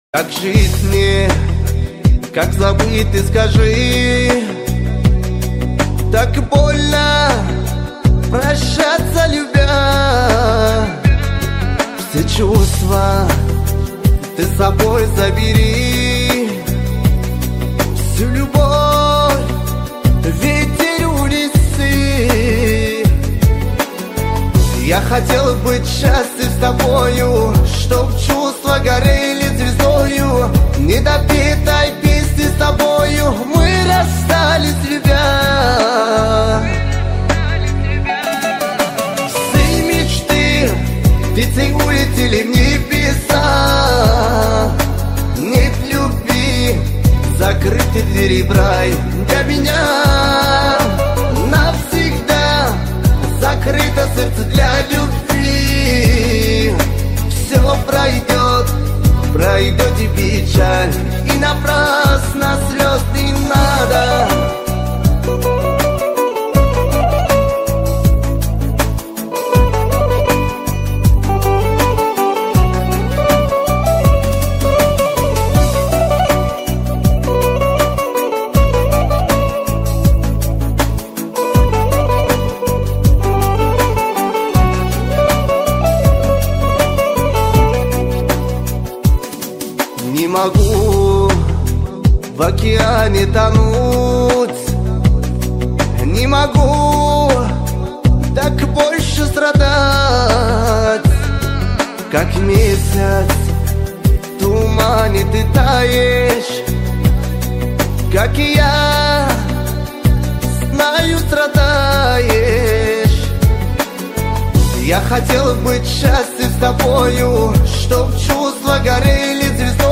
Красивая Кавказская песня